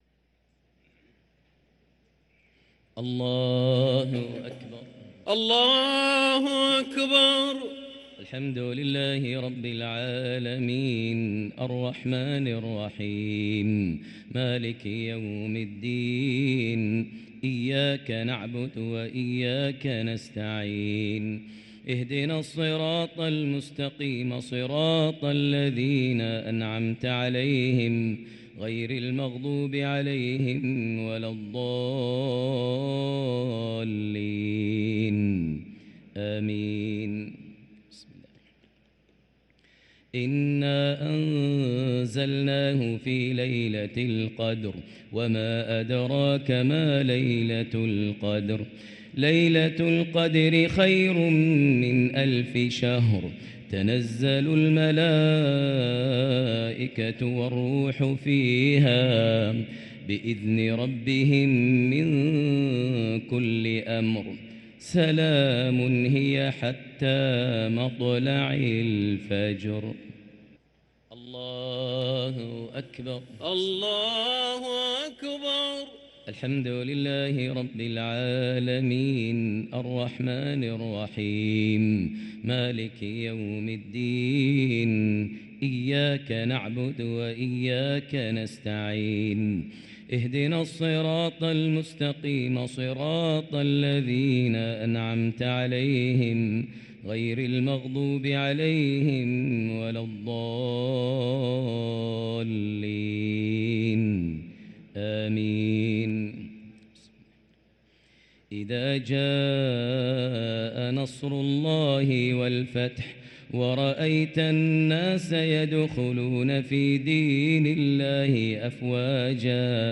الشفع و الوتر ليلة 24 رمضان 1444هـ > تراويح 1444هـ > التراويح - تلاوات ماهر المعيقلي